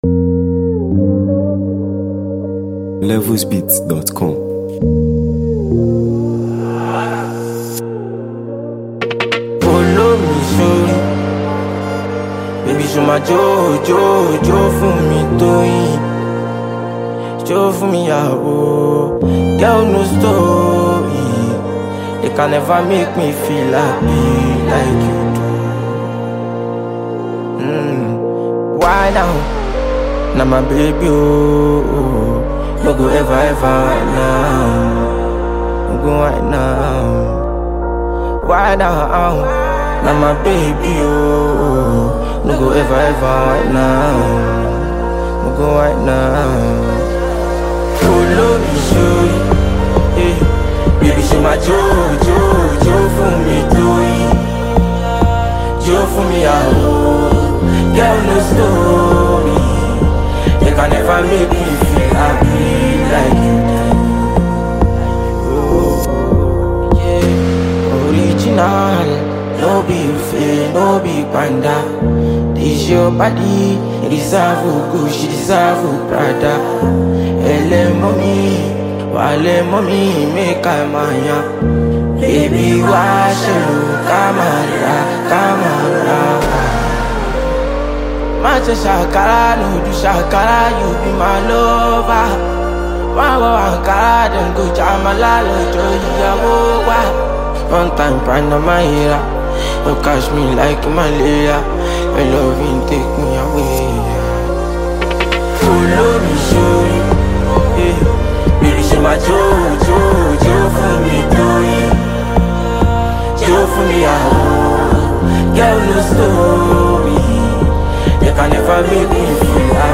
signature melodic flow, catchy hooks, and raw street energy
• Genre: Afrobeats / Street-Pop